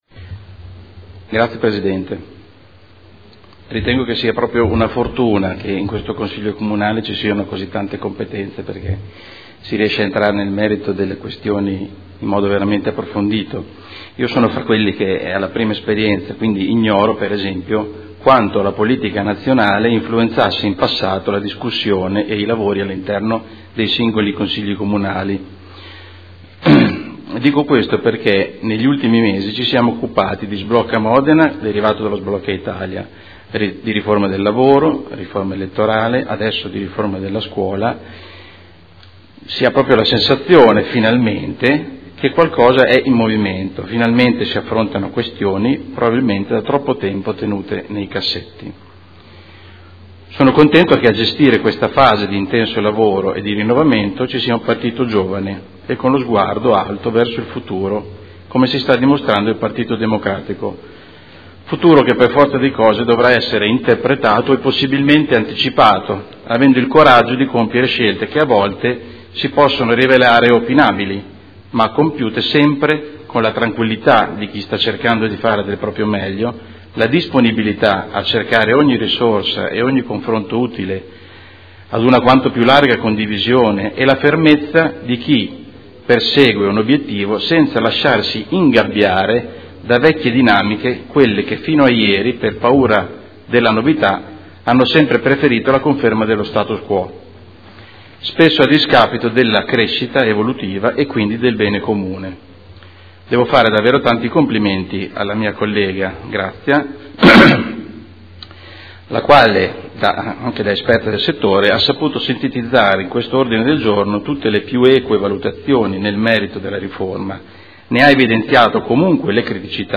Carmelo De Lillo — Sito Audio Consiglio Comunale
Seduta del 28/05/2015. Dibattito su Ordine del Giorno presentato dai consiglieri Baracchi, Arletti, Pacchioni, Bortolamasi, Fasano, Carpentieri, Stella, Lenzini, Liotti, De Lillo, Venturellli.